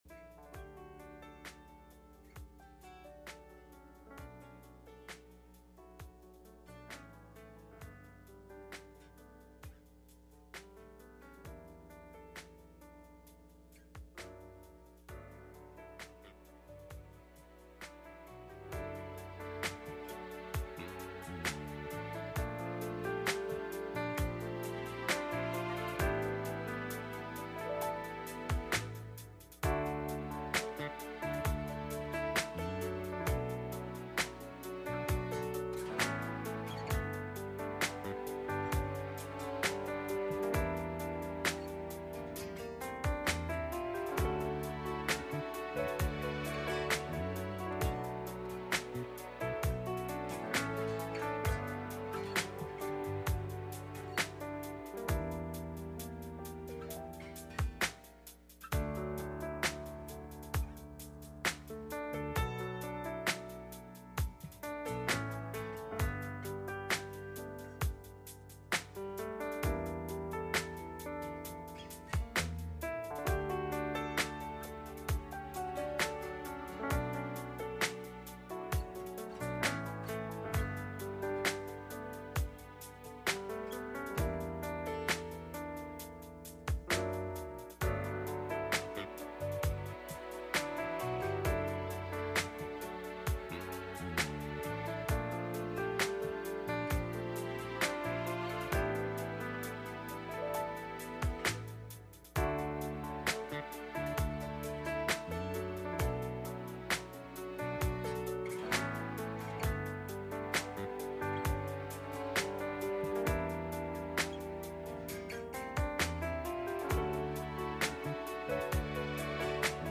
Wednesday Night Service
Midweek Meeting